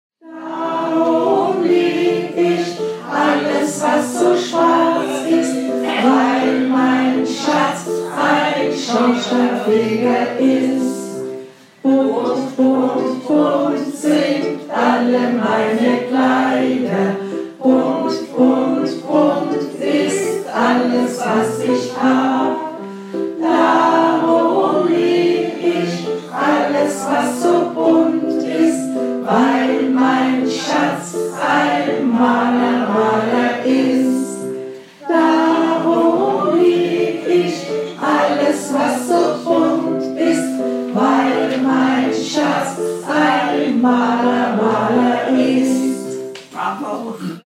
gesungen mit einer Seniorengruppe